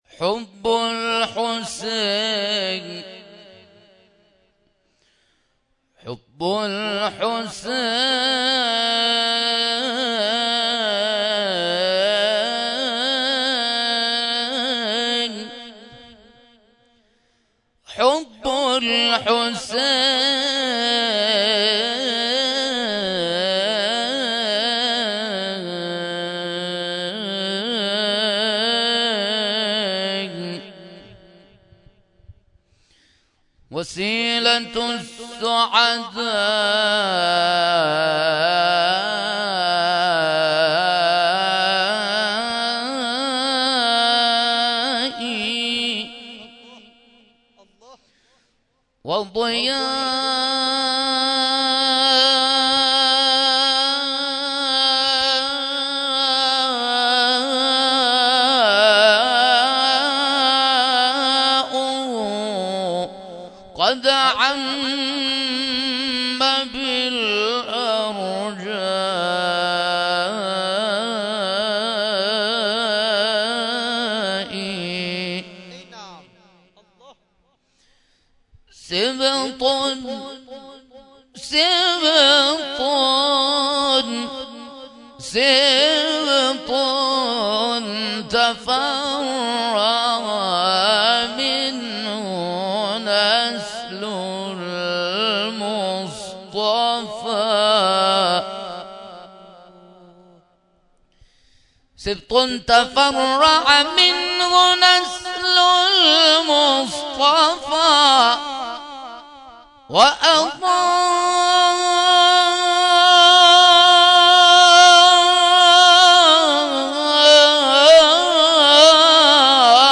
قاری بین‌المللی کشورمان با حضور در جمع عزاداران حسینی در تکیه تجریش علاوه بر تلاوت آیاتی از کلام‌الله مجید به اجرای ابتهال «حب الحسین» پرداخت.
در این مراسم علاوه بر مرثیه‌خوانی ذاکران اهل بیت عصمت و طهارت، قاریان ممتاز و بین‌المللی کشورمان نیز به تلاوت پرداختند.
ابتهال حب الحسین
تلاوت